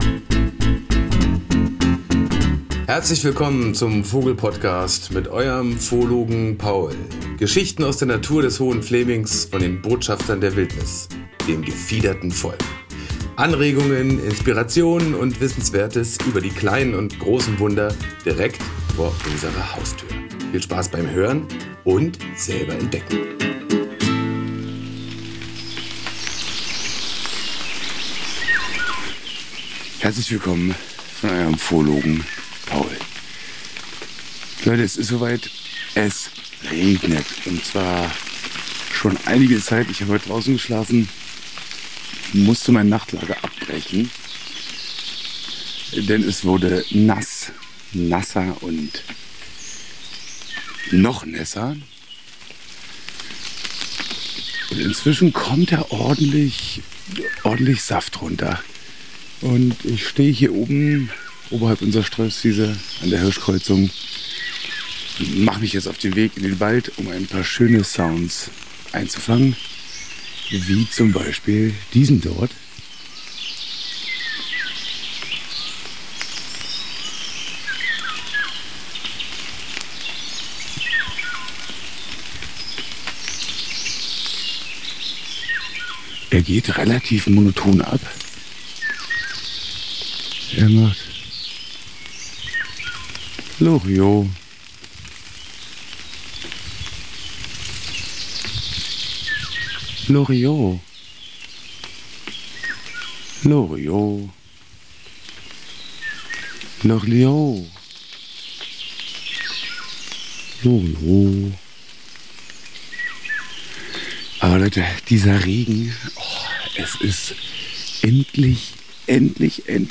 Nach über acht Wochen Trockenheit, endlich der erste ernst zu nehmende Regen. Der Wald ist nass. Wir hören Klangbeispiele von Wassertropfen auf Eichenlaub, Buchenlaub, durchtränktem Boden, untermalt von herrlichen Gesängen des Pirols, der Rotkehlchen, kräftig, schmetternden Buchfinken und vielen weiteren Vögeln.